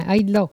Il crie pour chasser le chien ( prononcer le cri )
Catégorie Locution